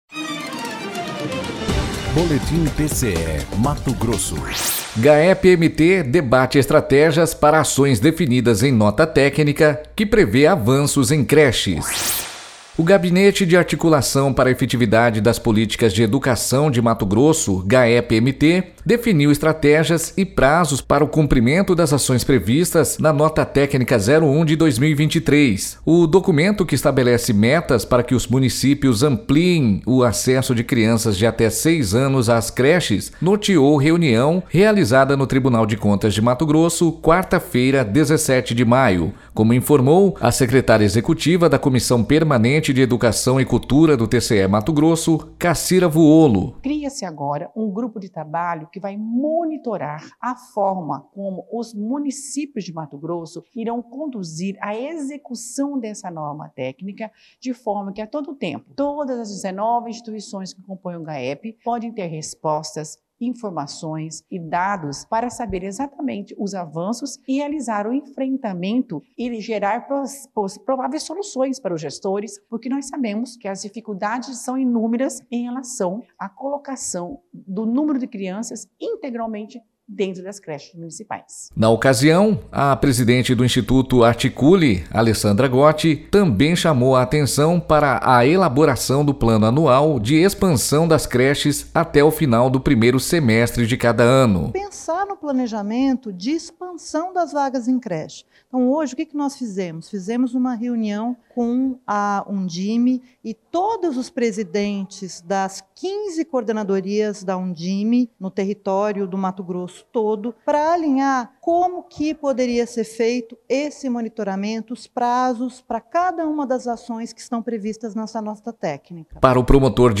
Sonora: Miguel Slhessarenko - promotor de Justiça do Ministério Público do Estado